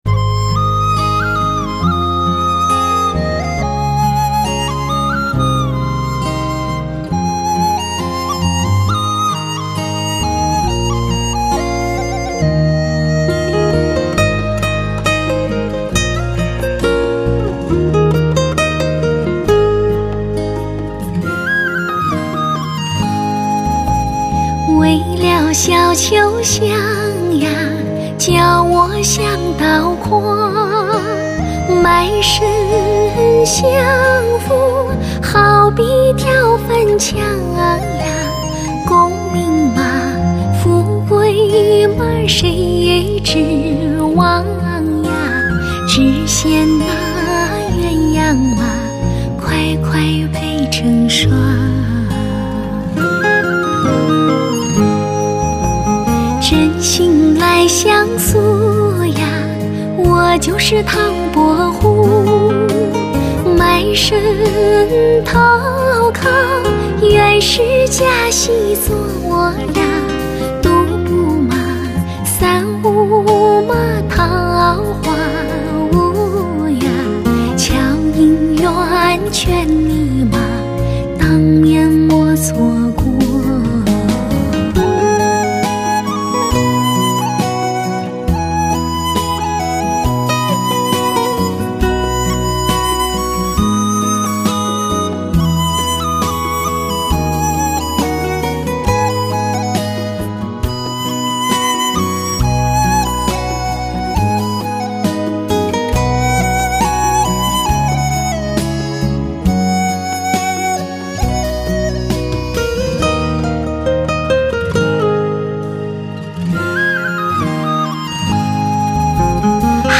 极富传奇色彩的动人经典唱段，靓绝人寰的歌声，韵味浓郁迷人，唱尽情爱悲欢世间百态。
千回婉转的歌唱，清秀脱俗 娇美动人，唱艺实而不华。
黄梅戏，旧称黄梅调或采茶戏，与京剧、越剧、评剧、豫剧并称中国五大剧种。